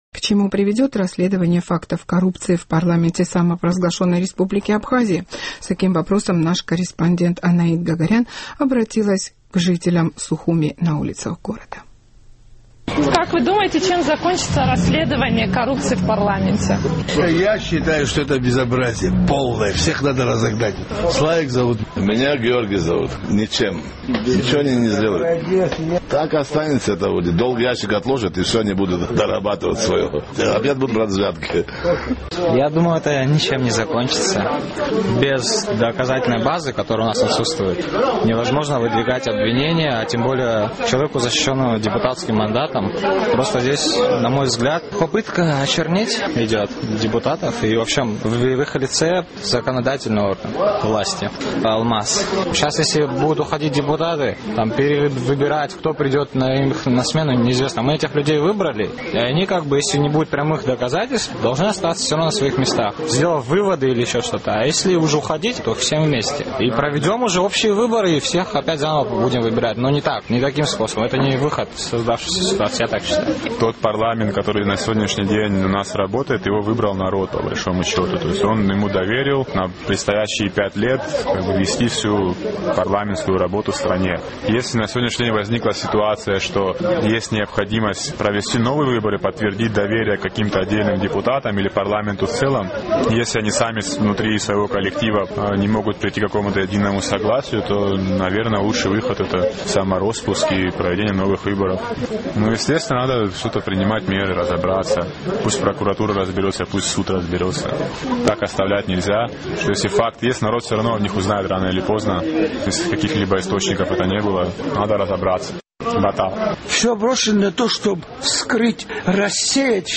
К чему приведет расследование фактов коррупции в парламенте республики Абхазия? С таким вопросом наш сухумский корреспондент обратилась на улицах к жителям абхазской столицы.